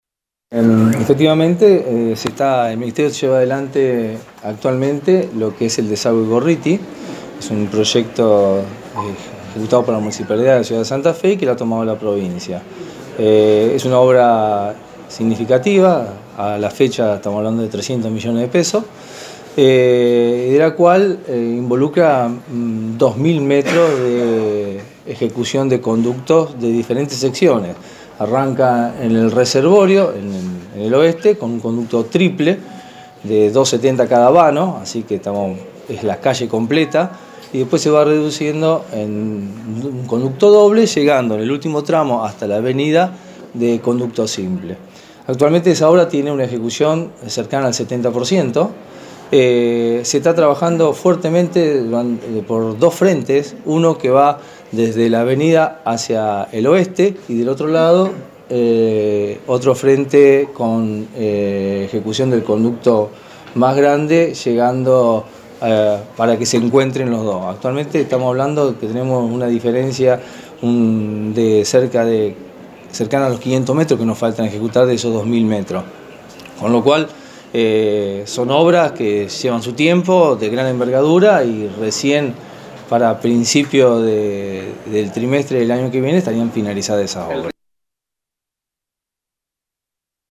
El director de Obras Hidraulicas, Renato Zonta fue quien se dirigió a la prensa sobre los trabajos realizados y mencionó al desague Gorriti, al cual consideró como una obra significativa que costó 300 millones de pesos.